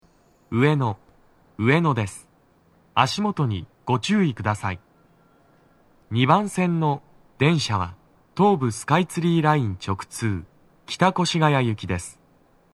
スピーカー種類 TOA天井型
足元注意喚起放送が付帯されています乗降が多く、フルはそこまで粘らず録れます
男声